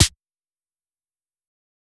SNARE_FINALLY.wav